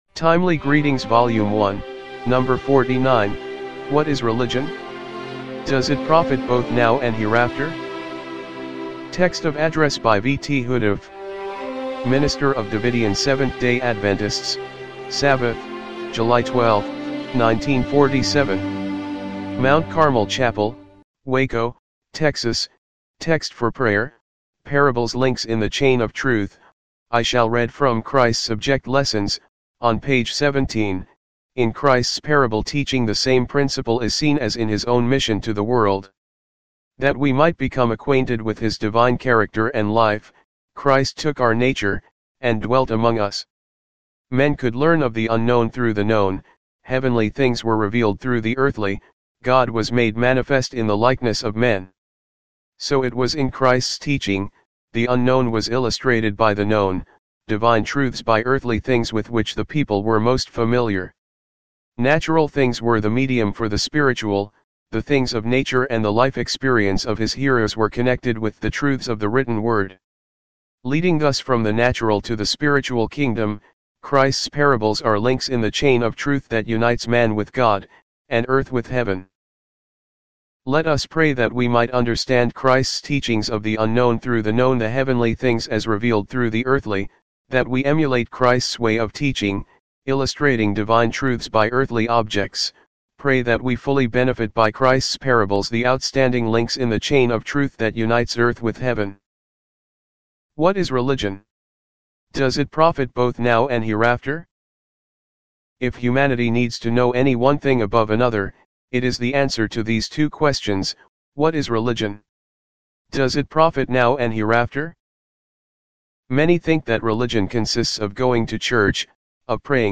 TEXT OF ADDRESS
1947 MT. CARMEL CHAPEL WACO, TEXAS
timely-greetings-volume-1-no.-49-mono-mp3.mp3